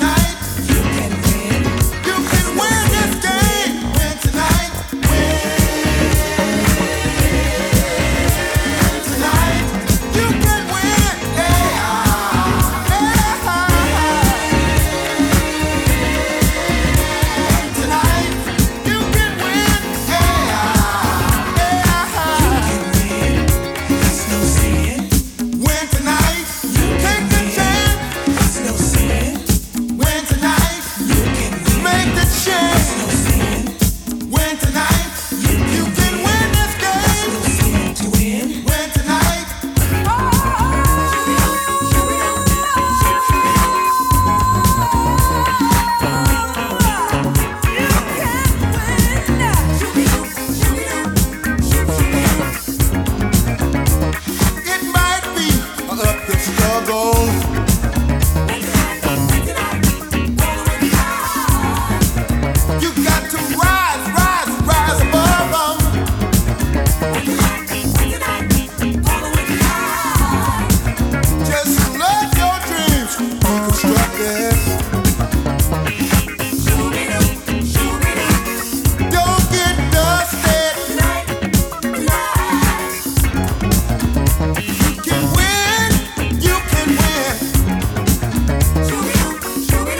ジャンル(スタイル) DISCO / SOUL / FUNK